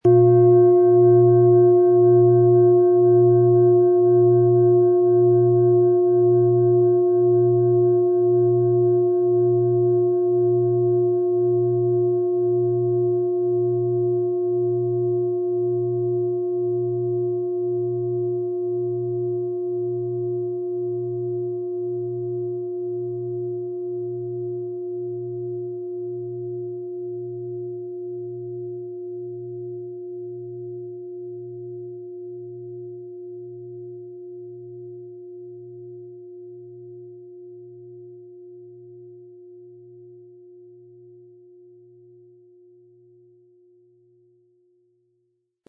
• Tiefster Ton: Mond
HerstellungIn Handarbeit getrieben
MaterialBronze